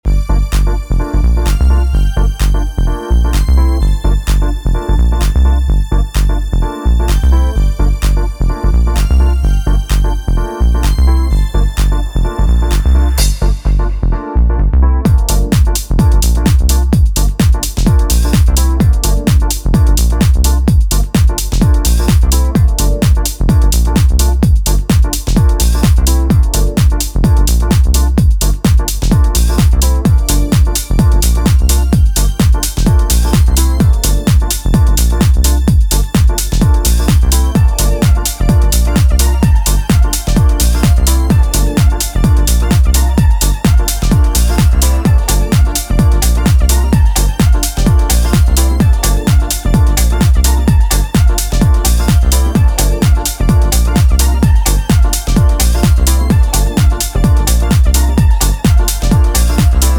garage and tribal house